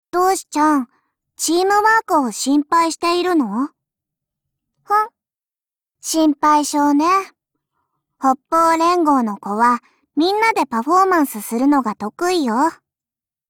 碧蓝航线:塔什干(μ兵装)语音